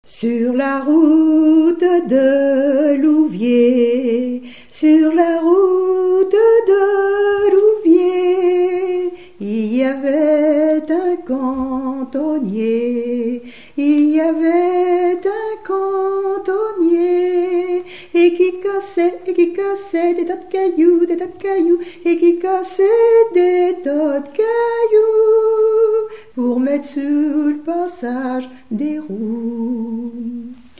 chanson de marche
Pièce musicale inédite